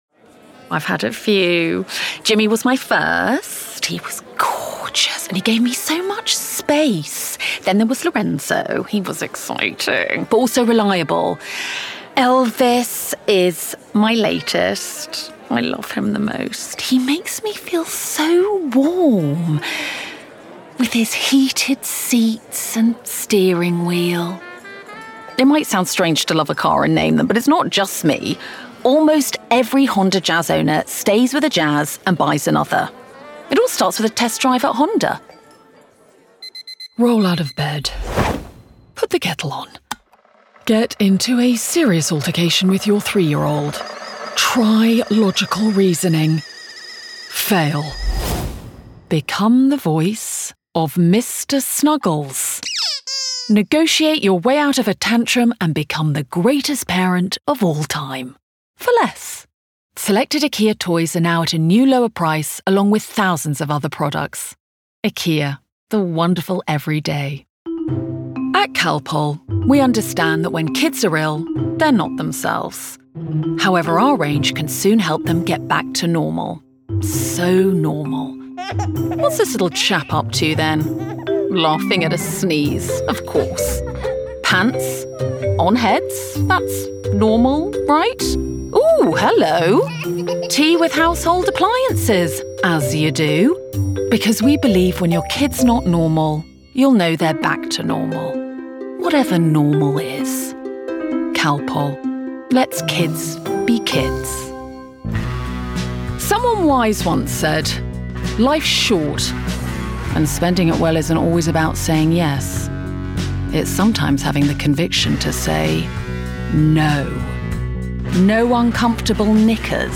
RP
Female
Assured
Bright
Dry
COMMERCIAL REEL